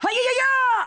Pit_voice_sample_EN_SSBB.oga.mp3